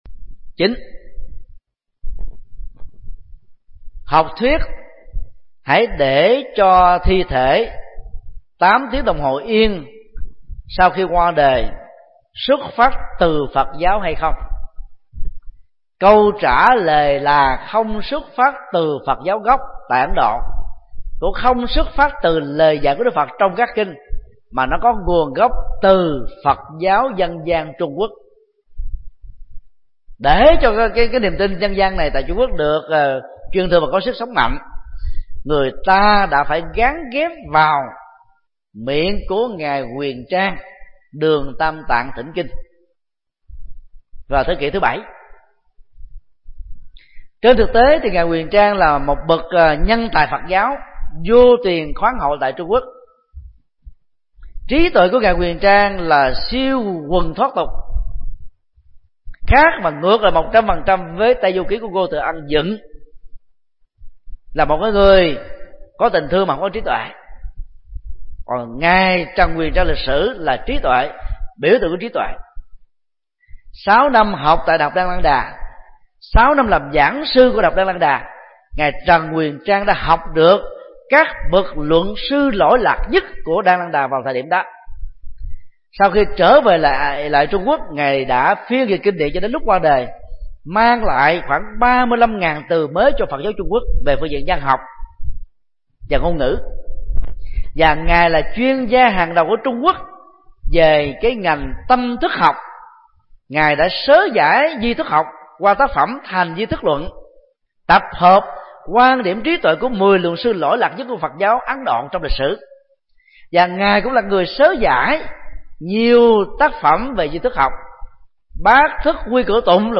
Vấn đáp: Quan niệm để thi thể 8 tiếng sau khi qua đời – Thích Nhật Từ